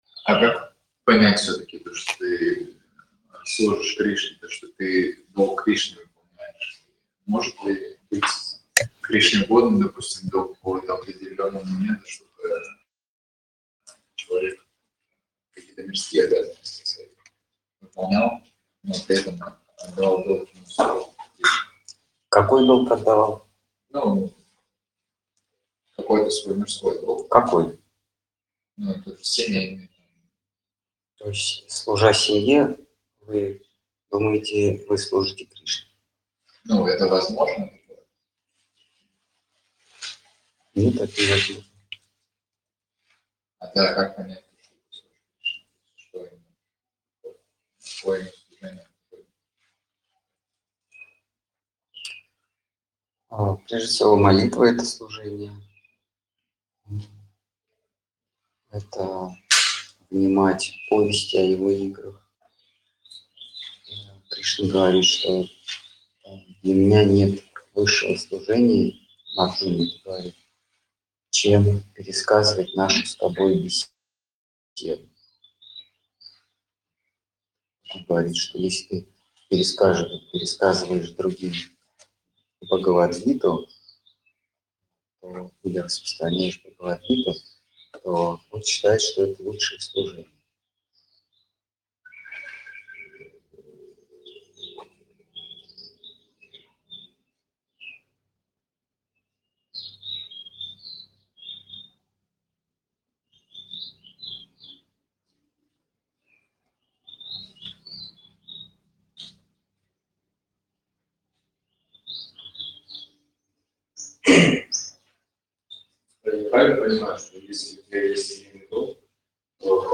Ответы на вопросы из трансляции в телеграм канале «Колесница Джаганнатха». Тема трансляции: Слово Хранителя Преданности.